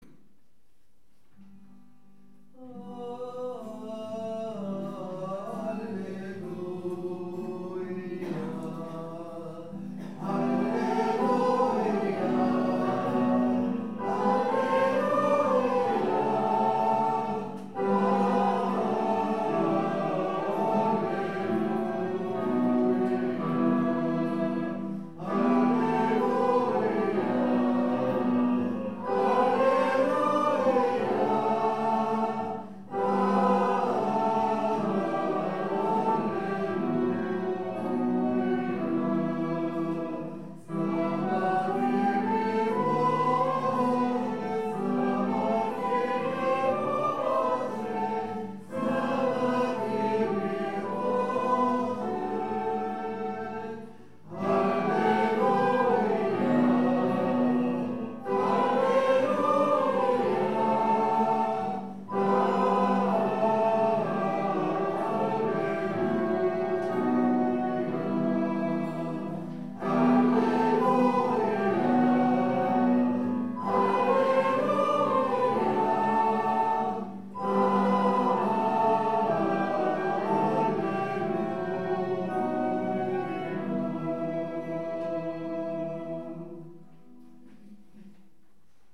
Pregària de Taizé
Capella de les Concepcionistes de Sant Josep - Diumenge 30 de novembre de 2014